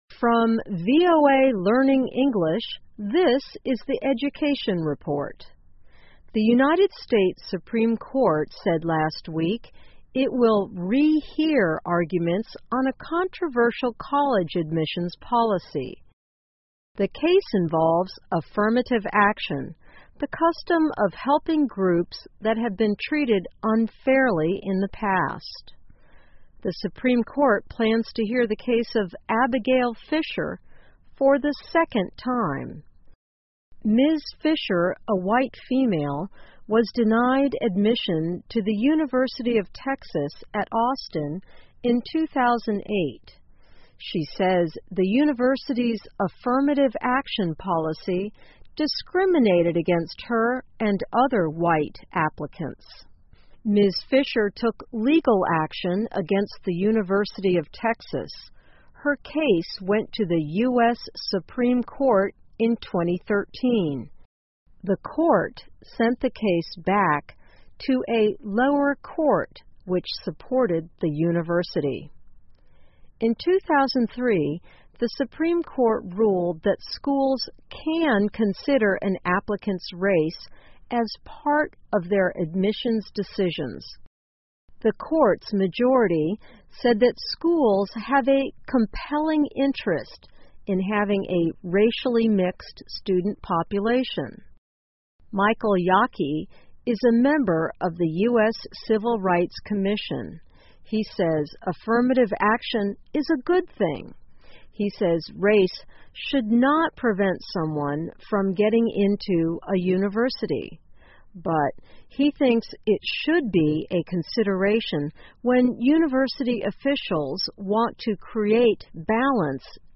在线英语听力室最高法院复审平权法案的听力文件下载,2015年慢速英语(七)月-在线英语听力室